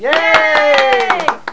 Cheer1
CHEER1.WAV